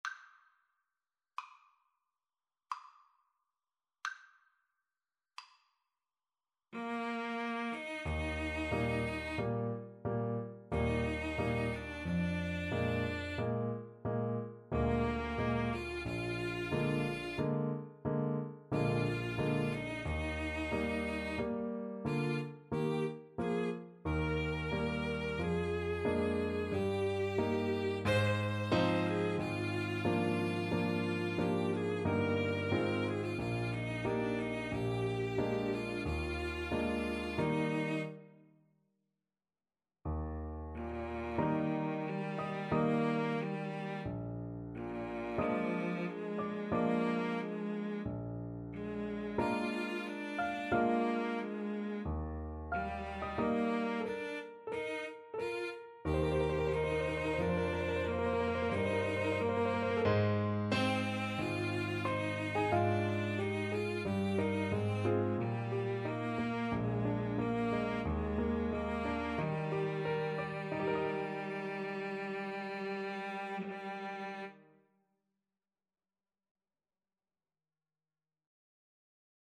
Adagio =45
Classical (View more Classical Mixed Trio Music)